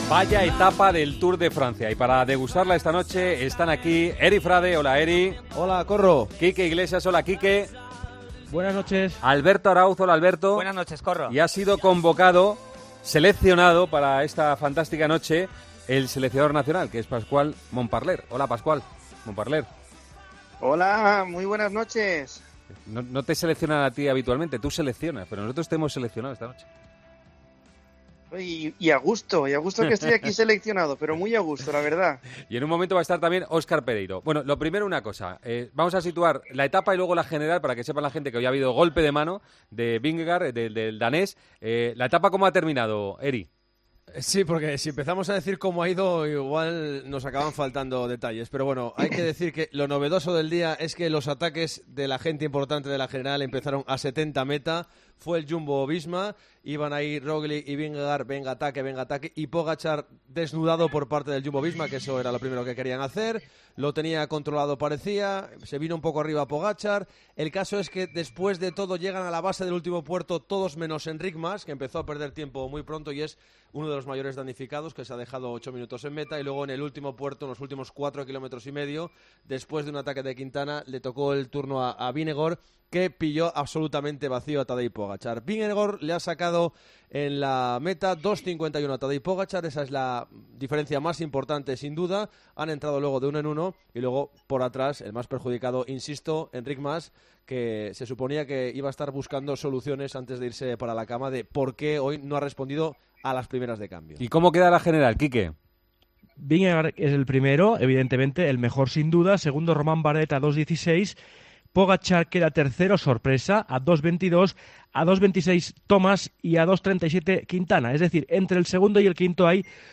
pasó bajo la lupa de los especialistas de la Cadena COPE